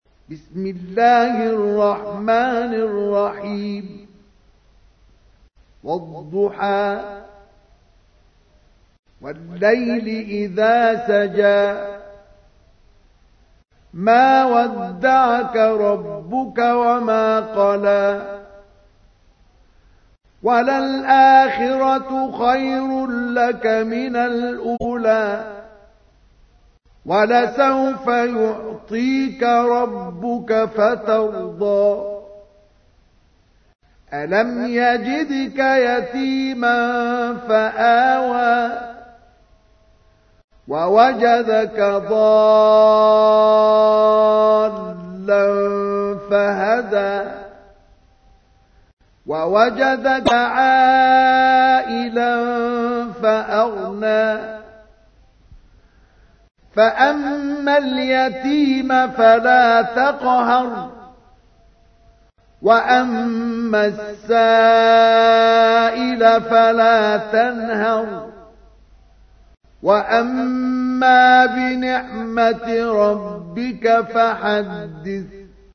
تحميل : 93. سورة الضحى / القارئ مصطفى اسماعيل / القرآن الكريم / موقع يا حسين